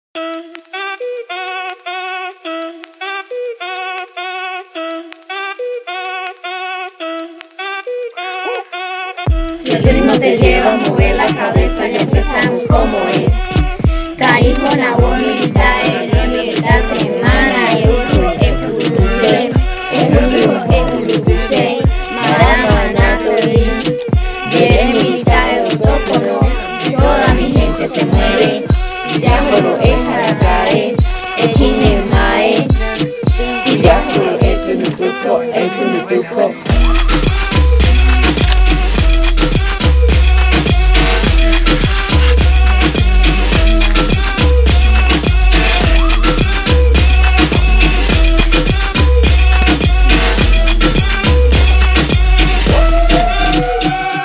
Puerto Nare, Guaviare
reggaeton song